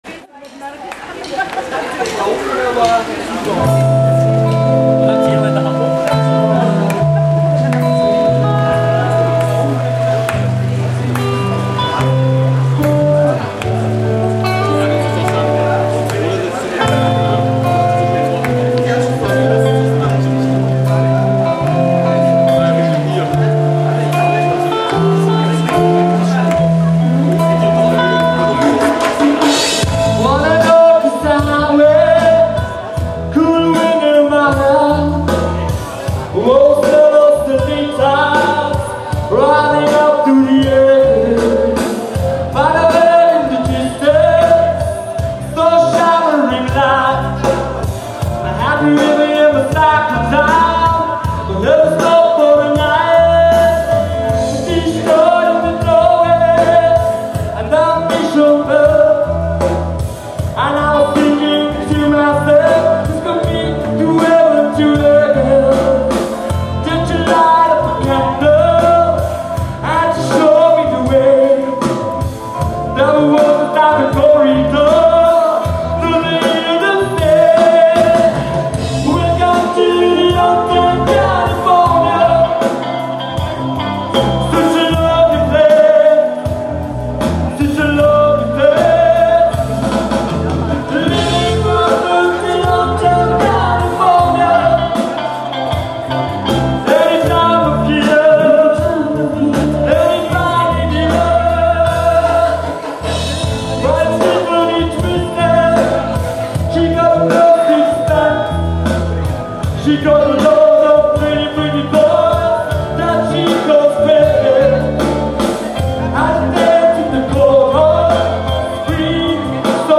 29.07.2006 "Ich hass mich"- Konzert in Haag
zum Soundcheck: